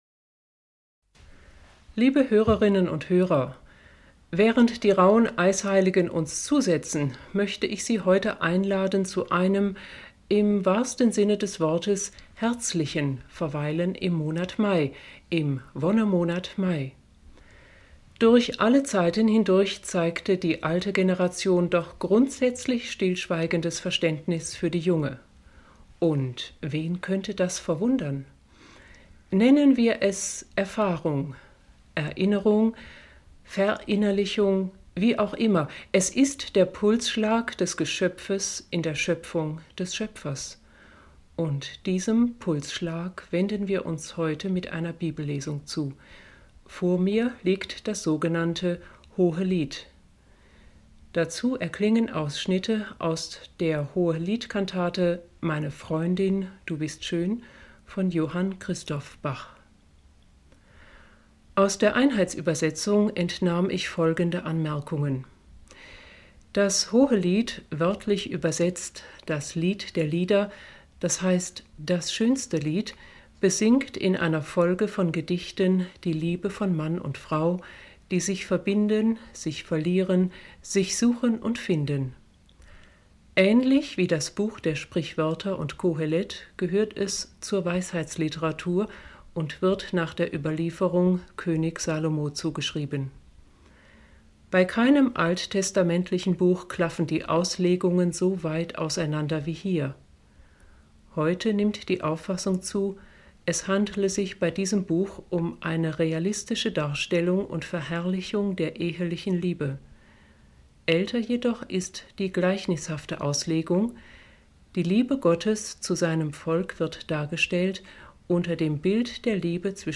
Radiosendung - émission radio
Einleitung in das Interview
Capriccio sopra il cucu (Orgel Münster / Goms)